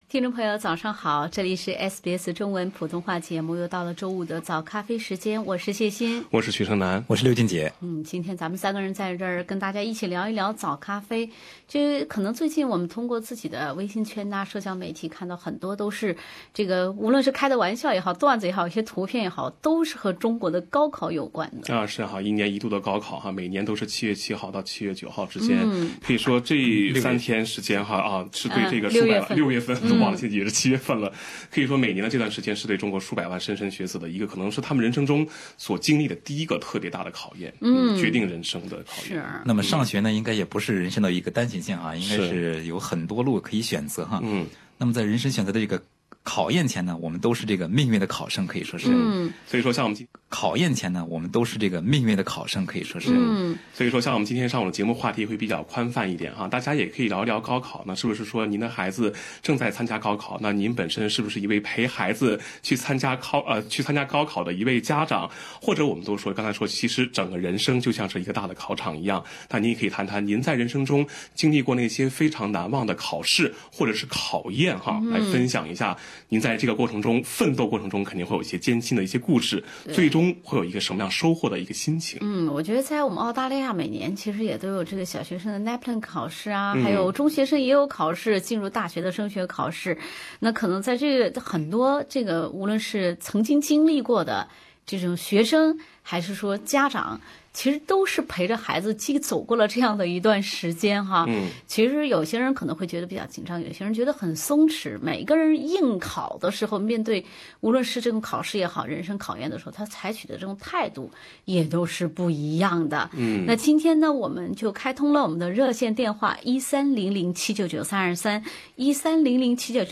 今天8点30分《早咖啡》节目，听众朋友和我们一起回味了高考相关的难忘考试和考验。